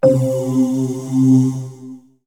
Ooohhh
ooohhh.wav